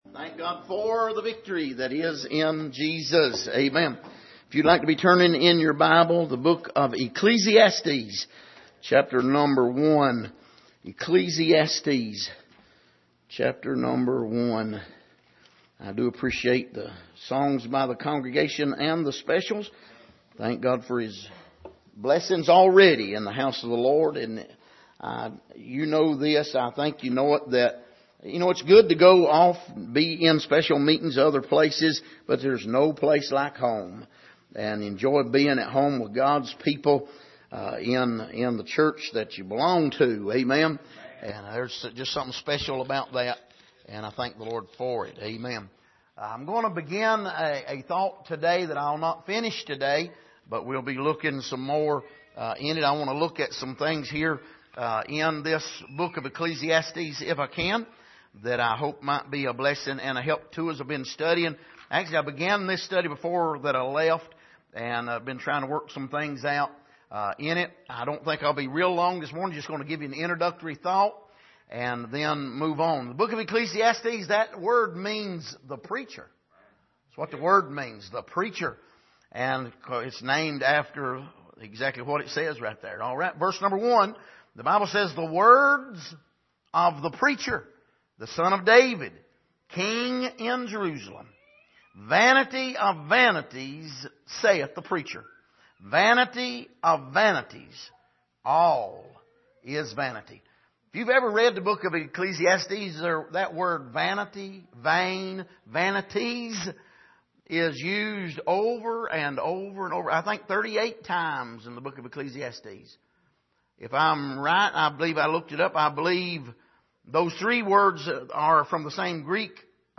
Series: Studies in Ecclesiastes Passage: Ecclesiastes 1:1 Service: Sunday Morning